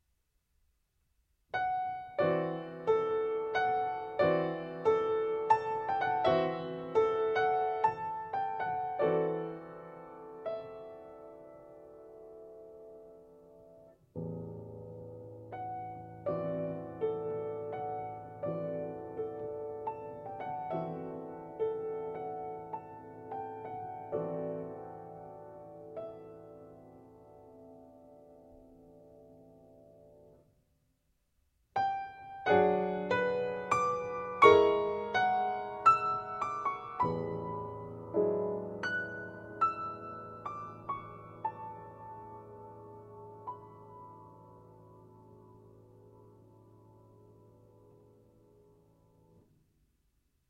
Semplice http